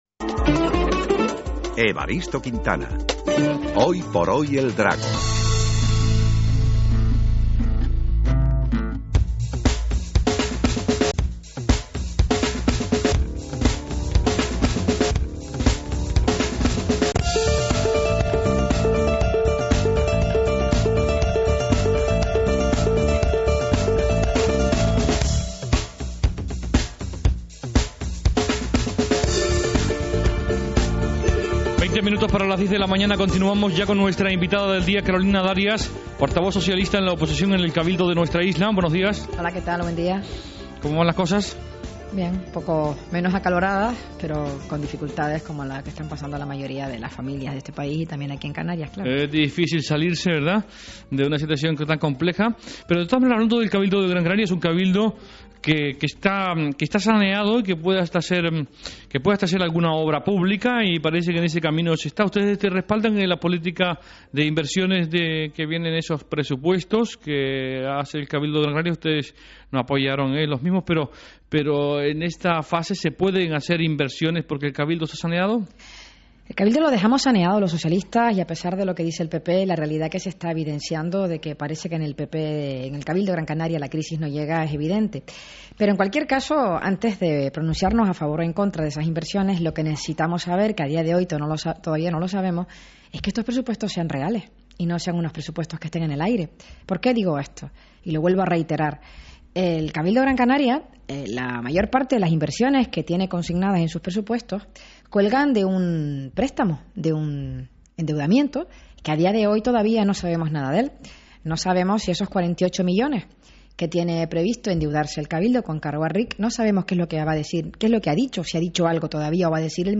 Como siempre, un placer pisar los estudios de Ser Las Palmas.
ENTREVISTA CAROLINA DARIAS SER 17 MAYO